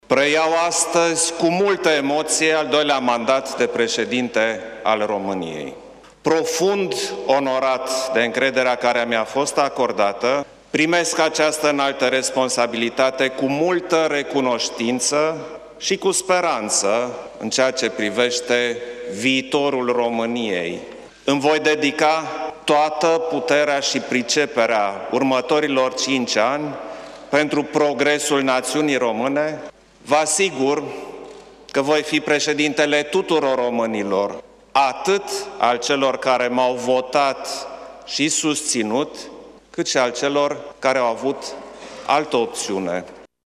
Ceremonia de învestitură în funcţie a avut loc în faţa plenului reunit al Camerei Deputaţilor şi a Senatului.
Klaus Iohannis a declarat că preia cu multă emoţie al doilea mandat de şef al statului şi a dat asigurări că va fi preşedintele tuturor românilor:
stiri-21-dec-iohannis-investitura.mp3